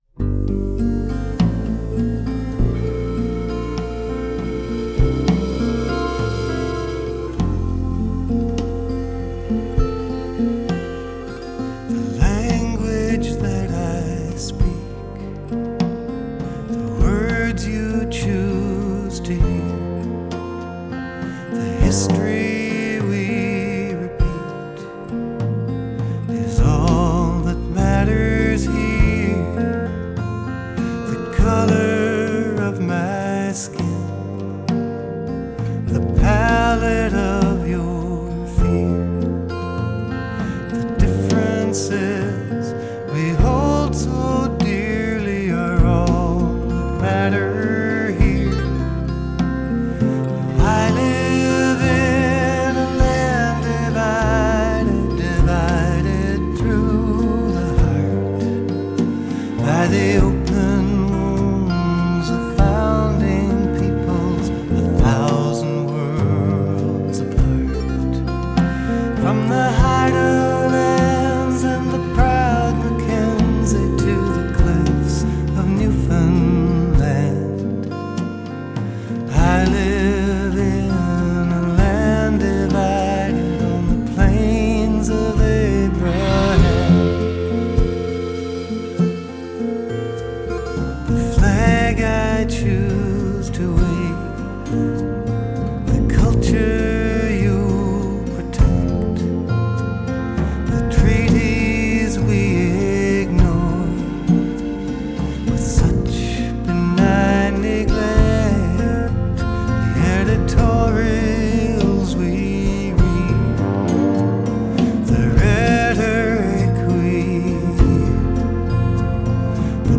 Pop / RockStories/HistoricalBallad